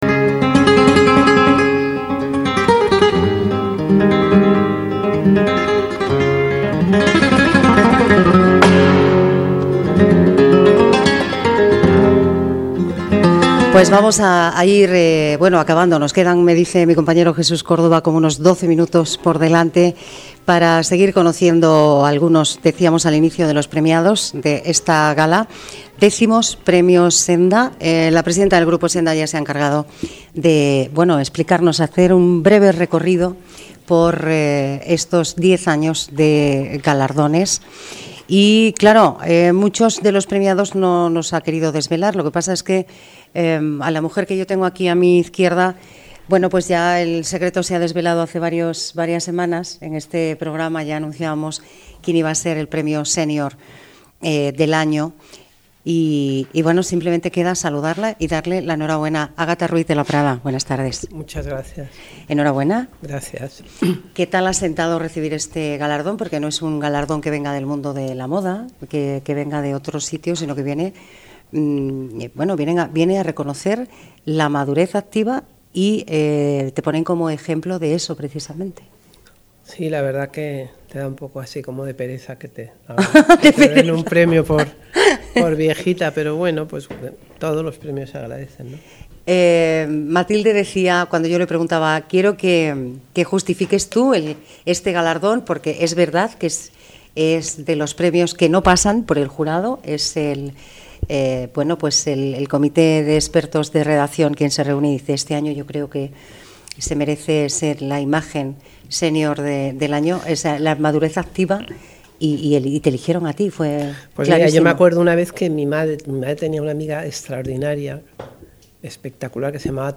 Así de contundente se mostraba la diseñadora y empresaria Ágatha Ruiz de la Prada durante la entrevista que mantuvo en el programa que el Grupo SENDA produce en Radio Internacional, Palabras Mayores. Programa realizado justo antes de la gala de entrega de los X Premios Senda, el pasado 17 de septiembre.
Escucha la entrevista que concedió a Palabras Mayores: